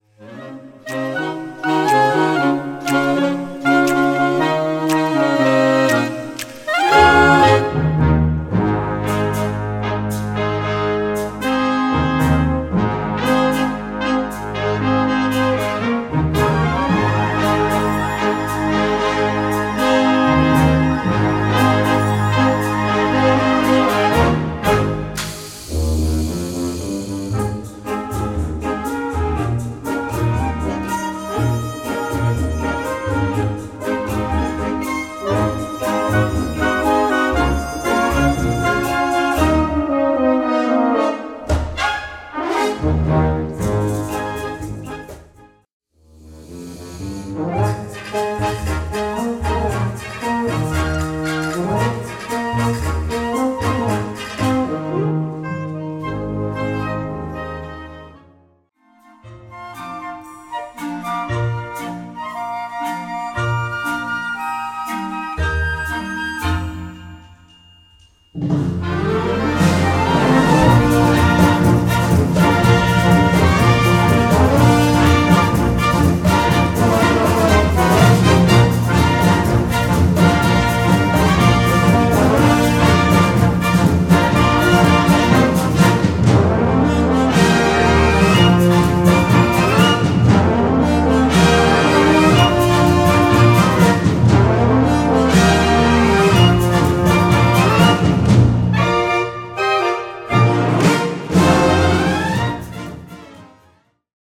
Gattung: Weihnachtslied
Besetzung: Blasorchester